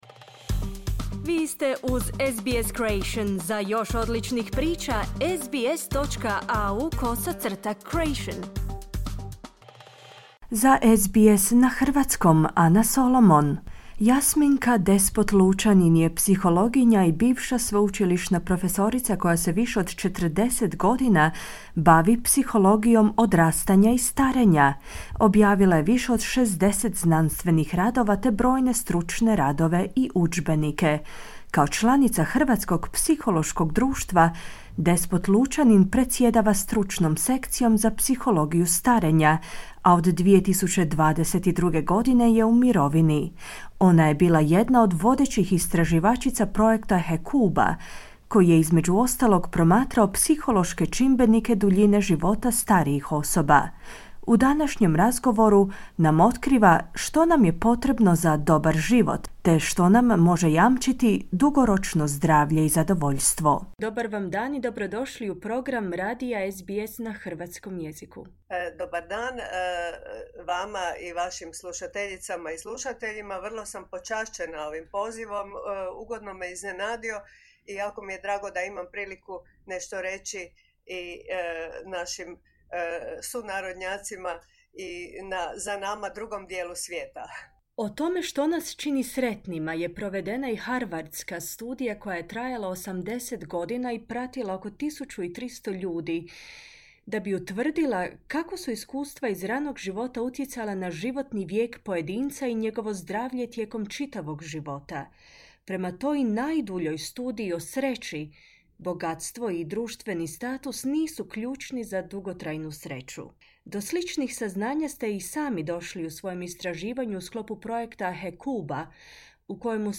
U današnjem razgovoru otkriva što nam je potrebno za dobar život, te što nam može jamčiti zdravlje i zadovoljstvo.